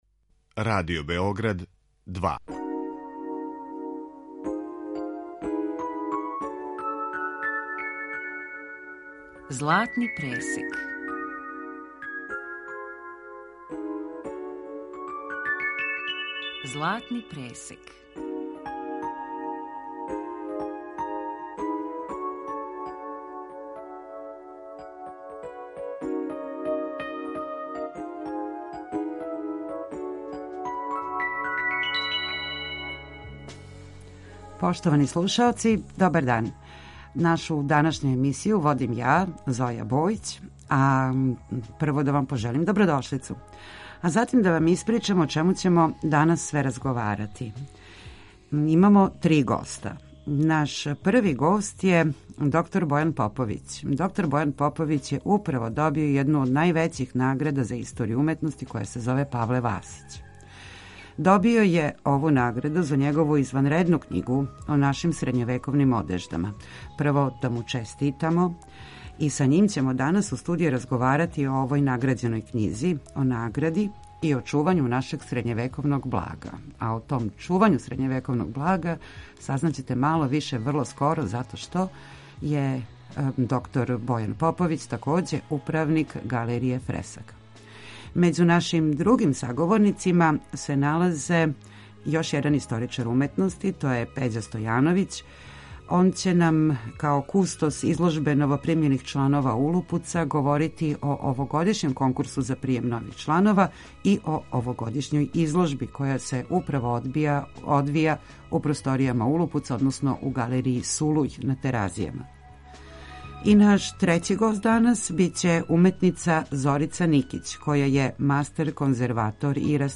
Честитамо добитнику ове значајне награде и са њим у студију данас разговарамо о његовој награђеној књизи, о награди и о чувању нашег средњевековног блага.